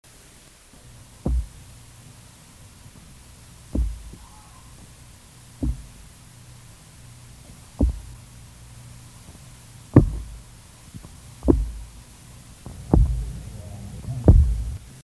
Sound produced yes, active sound production
Type of sound produced escape thumps, weak knocks, louder bumps
Sound production organ swim bladder
Behavioural context spontaneous escape sounds & bumps, knocks under duress (electric stimulation)
Remark recorded with bandpass filter: 30 - 1200 Hz, recording amplified by 6 dB